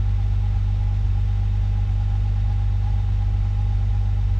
v12_02_idle.wav